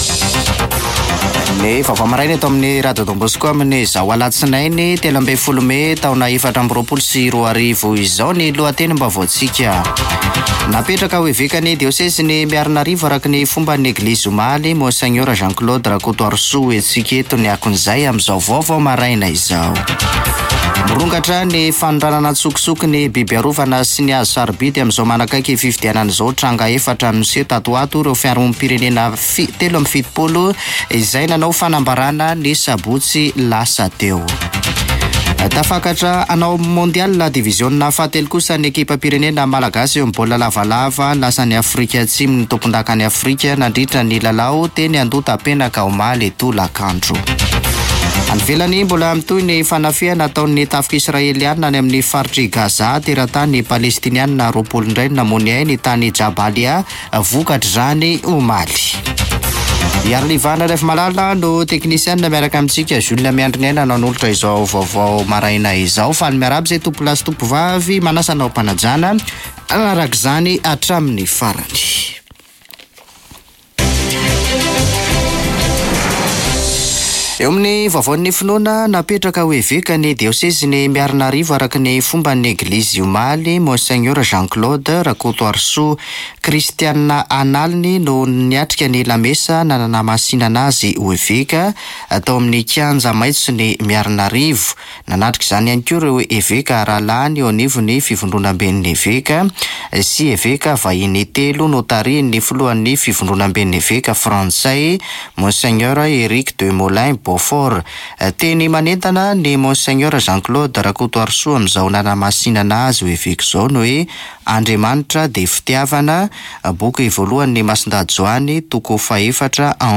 [Vaovao maraina] Alatsinainy 13 mey 2024